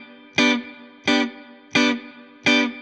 DD_StratChop_85-Gmaj.wav